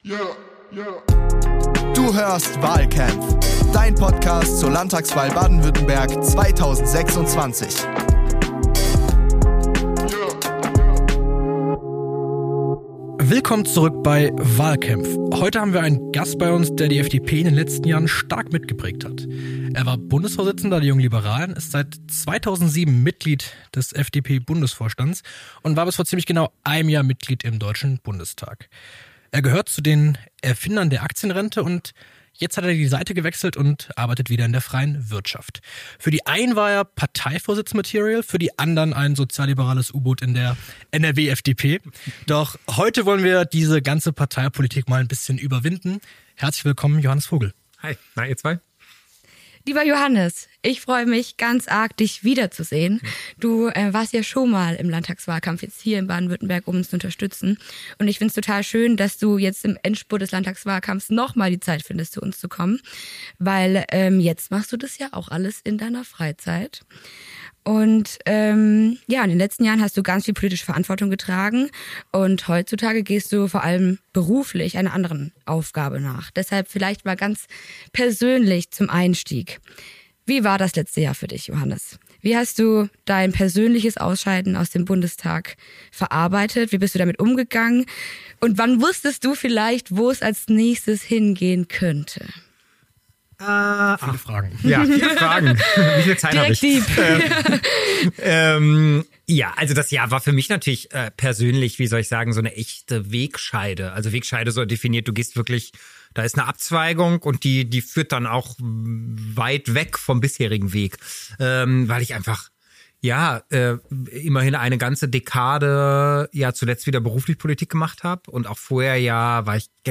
Mit etwas Abstand zur Berliner Blase wirft er nun einen schonungslos ehrlichen Blick zurück auf die Fehler der Ampel-Zeit und blickt als Unternehmenslenker auf den deutschen Arbeitsmarkt. Ein Talk über die Generationengerechtigkeit, verlorenes Vertrauen und die Frage, ob wir in Deutschland eigentlich wieder mehr arbeiten müssen.